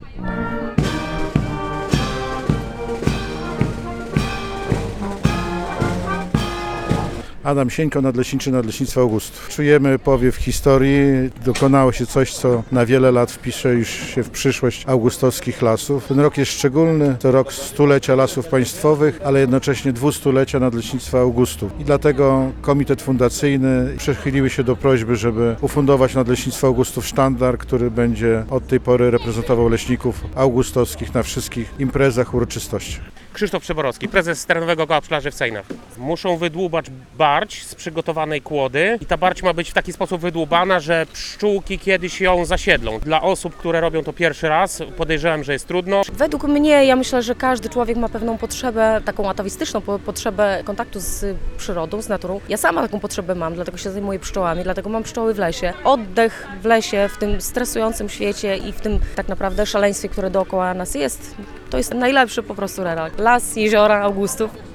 Festyn Królewskiej Puszczy Augustowskiej na Rynku Zygmunta Augusta w Augustowie